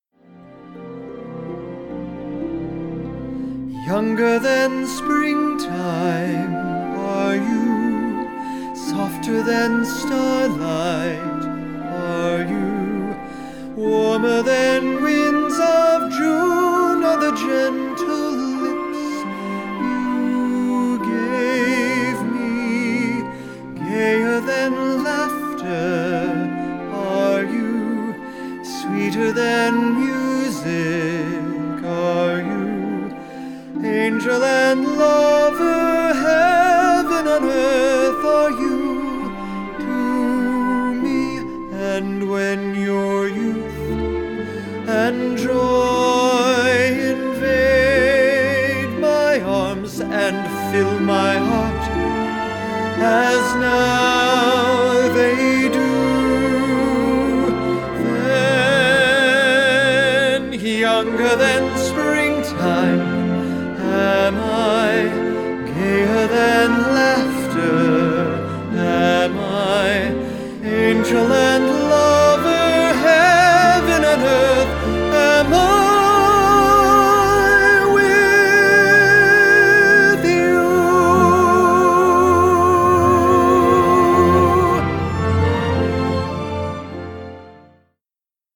Broadway